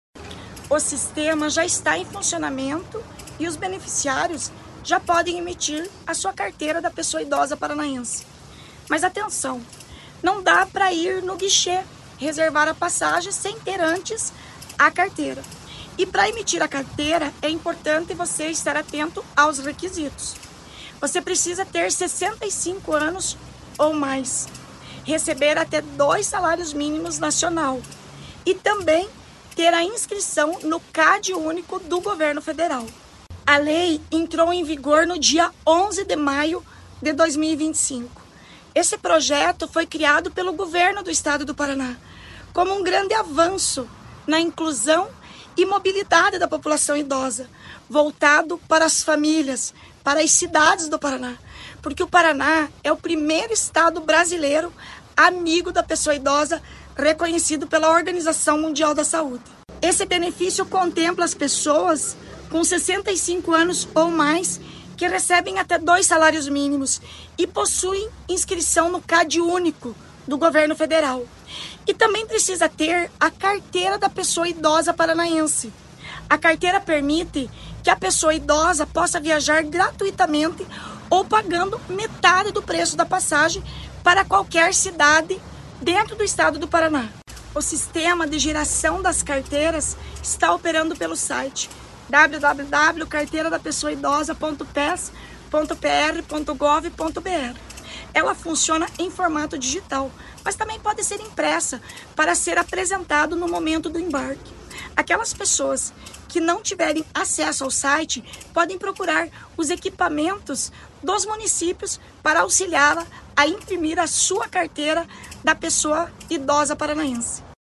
A lei determina que pessoas com 65 anos ou mais, com renda de até dois salários mínimos, inscritas no CadÚnico do governo federal e com a Carteira da Pessoa Idosa Paranaense podem requisitar passagem em linhas de ônibus intermunicipais. Ouça o que diz sobre o assunto a secretária da Mulher, Igualdade Racial e Pessoa Idosa, Leandre Dal Ponte: